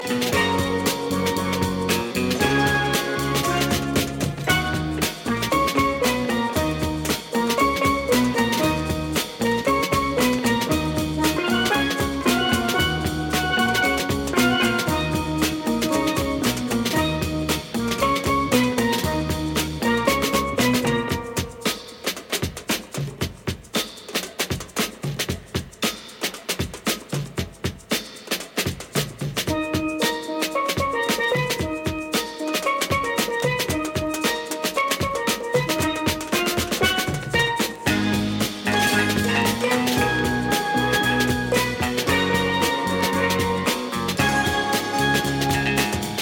funk 7"